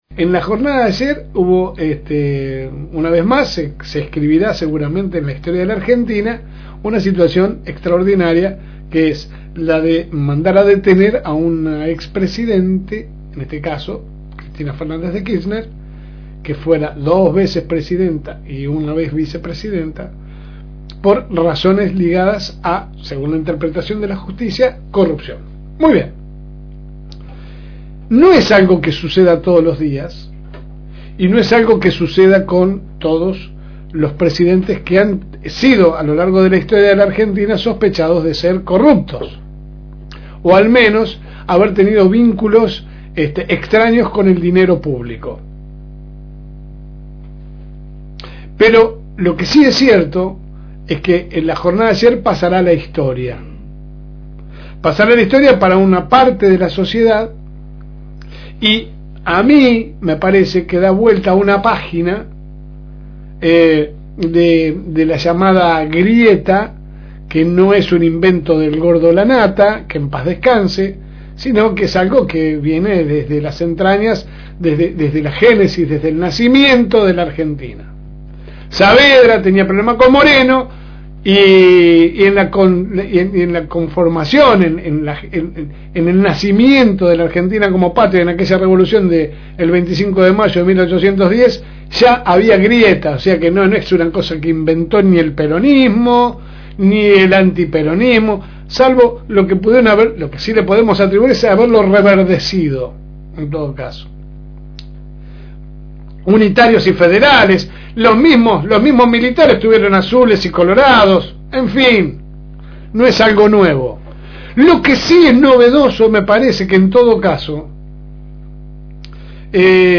La Segunda Mañana sale por el aire de la FM 102.9 de lunes a viernes de 10 a 12 HS